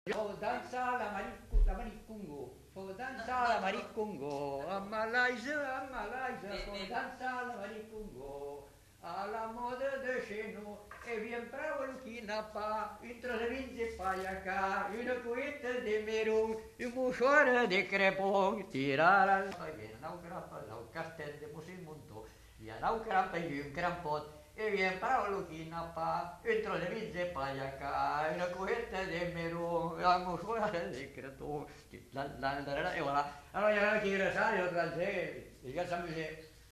Genre : chant
Effectif : 1
Type de voix : voix d'homme
Production du son : chanté
Danse : congo
Classification : chansons de neuf
Notes consultables : L'interprète semble se tromper dans les paroles au début du chant.